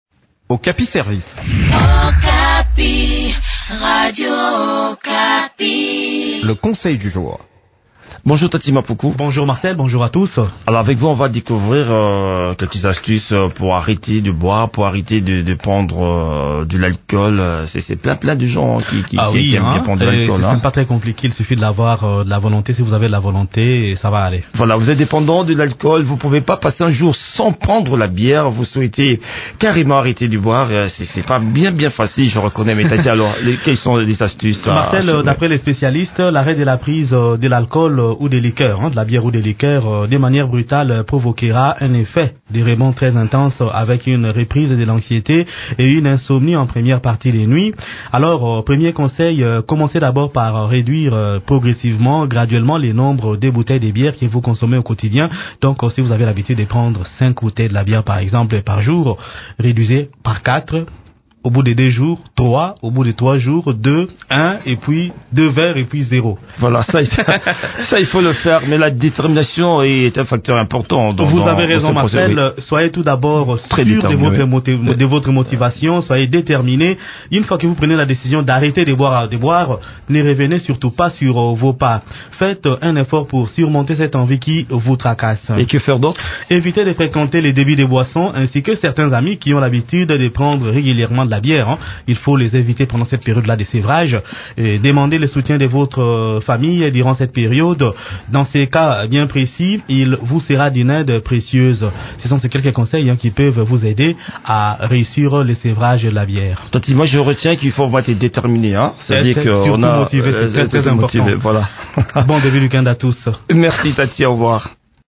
Découvrez quelques astuces qui peuvent vous aider à arrêter de boire dans cette chronique